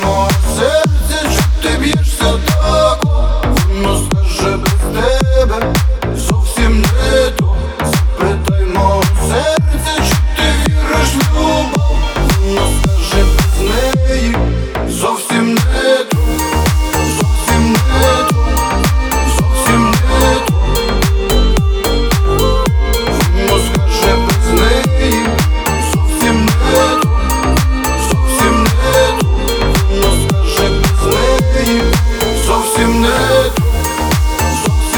Electronic Pop